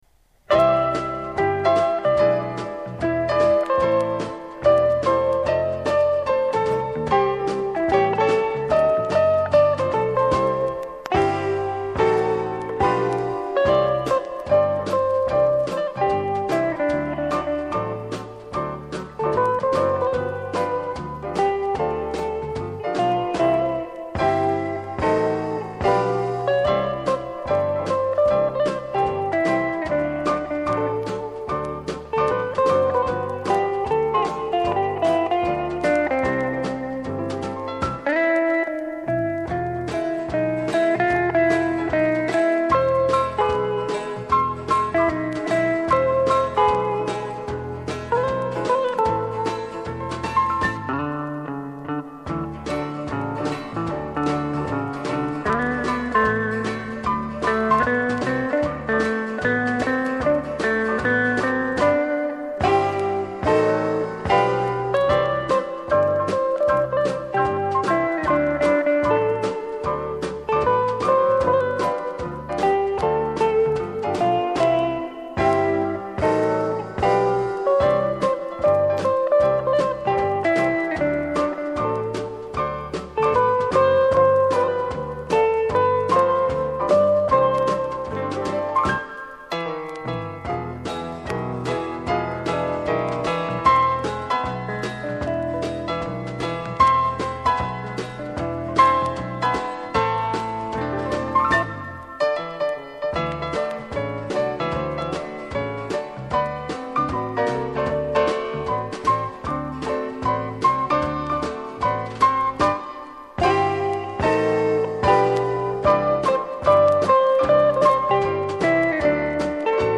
Известный гитарист.